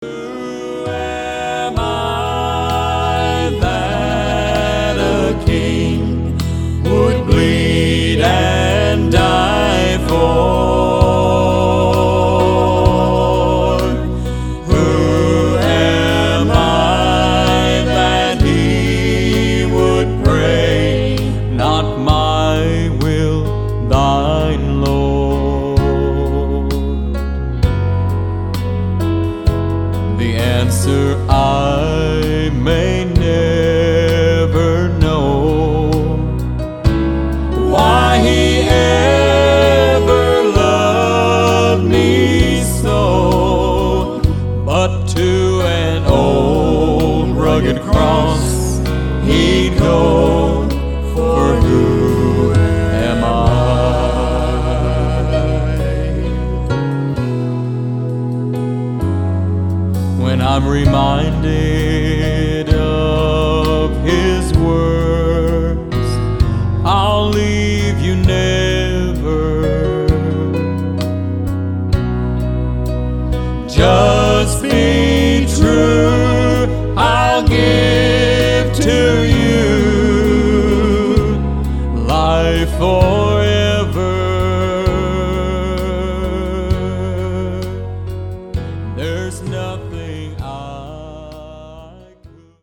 11 Southern Gospel Songs